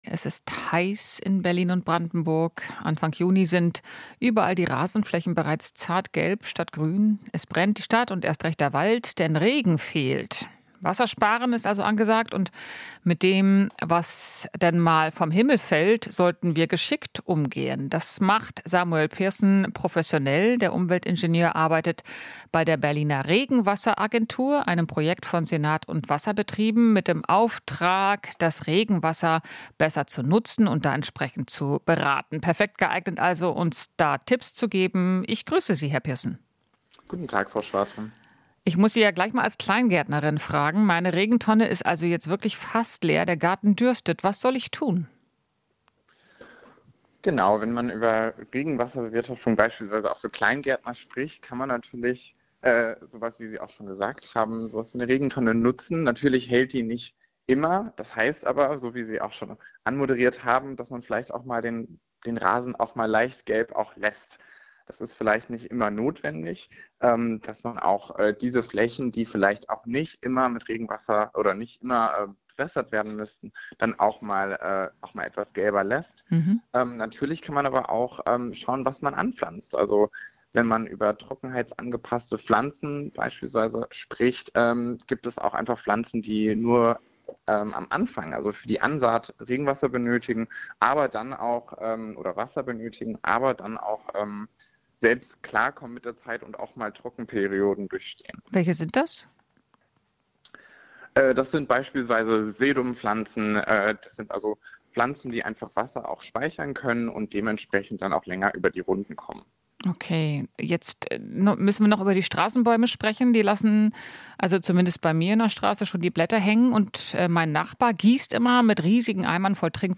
Interview - Regenwasseragentur: Berlin muss "in die Puschen kommen"